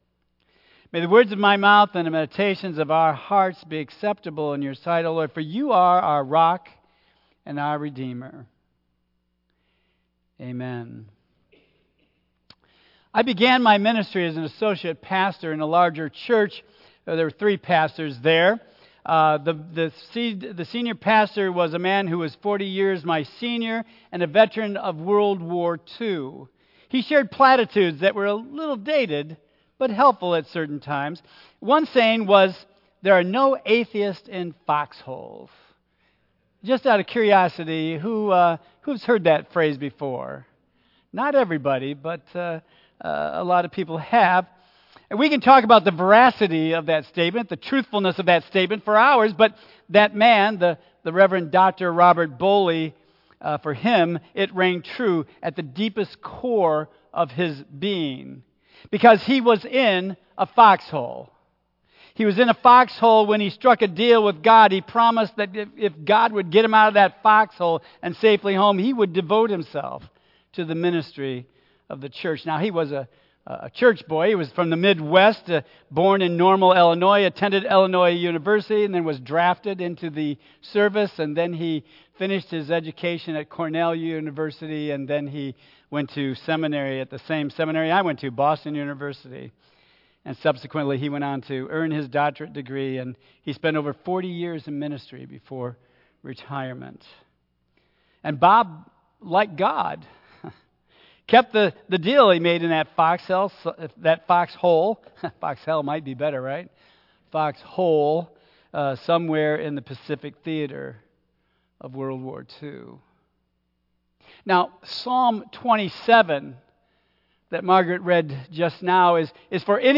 Tagged with Michigan , Sermon , Waterford Central United Methodist Church , Worship Audio (MP3) 8 MB Previous We Gather...to Celebrate Life Next We Gather...To Make a Difference In Our World